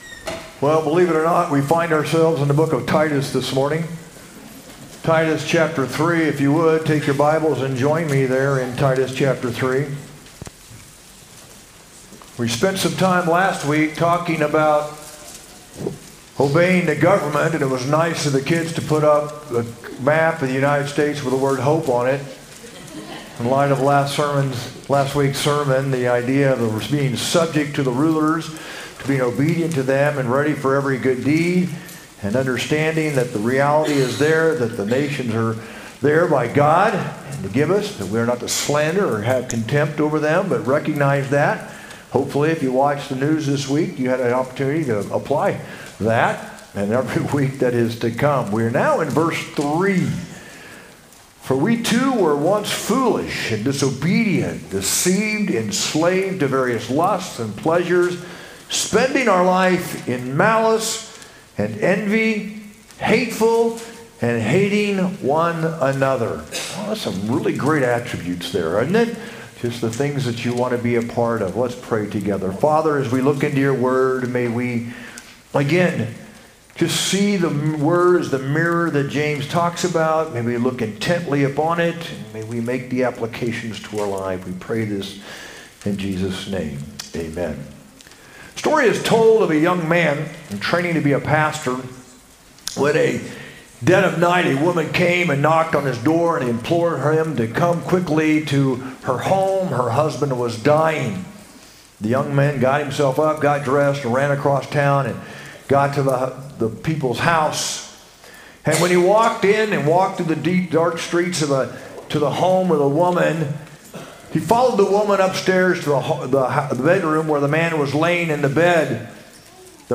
sermon-8-24-25.mp3